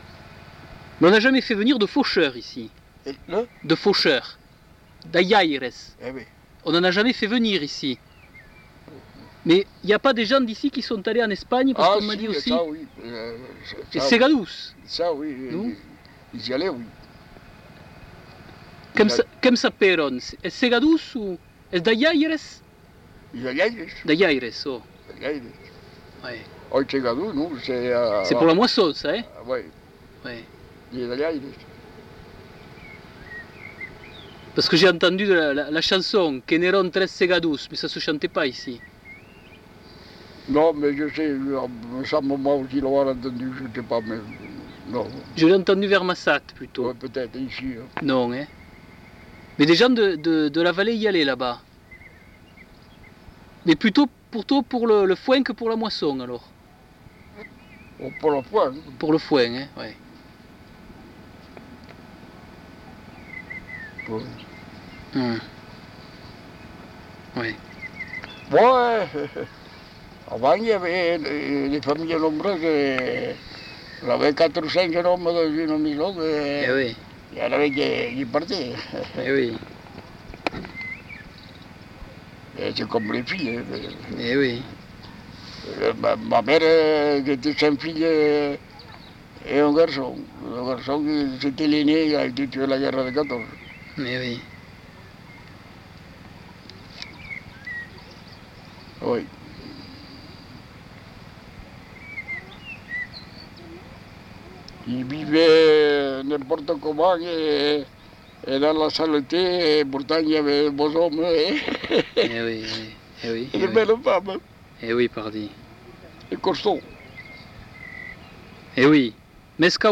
Genre : témoignage thématique
Ecouter-voir : archives sonores en ligne